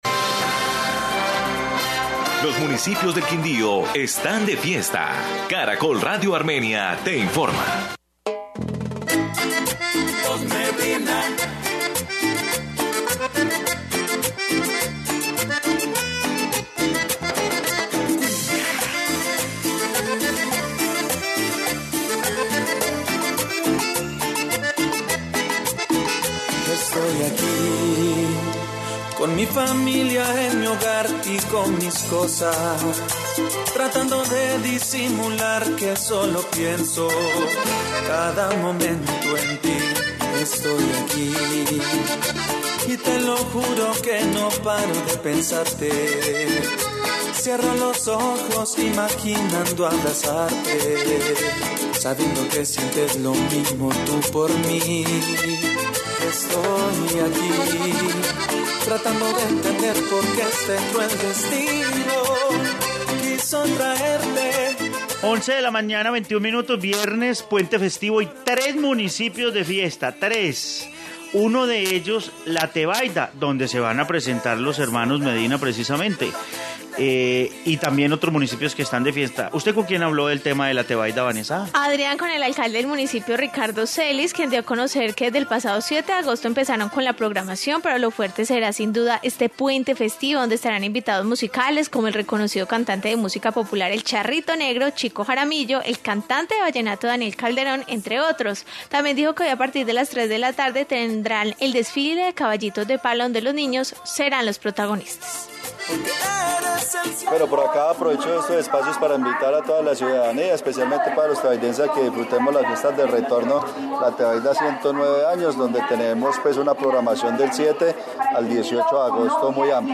informe fiestas de los municipios del Quindío